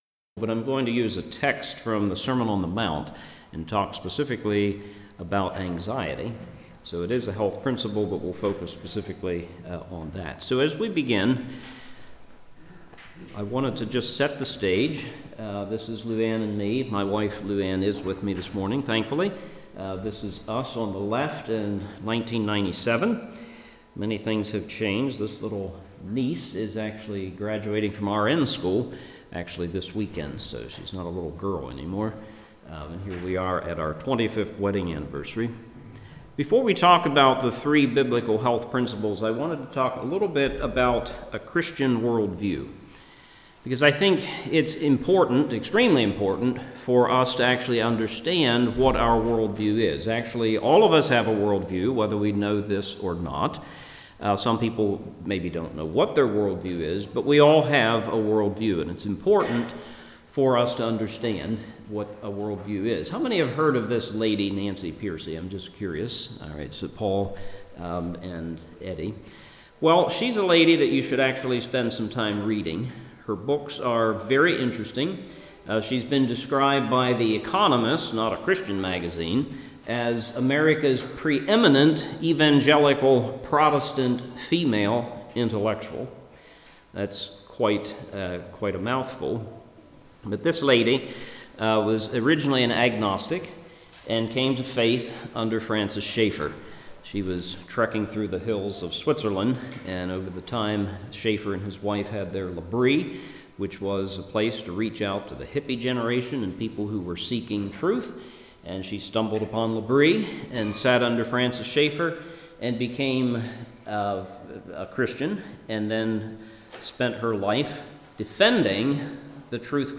Service Type: Seminar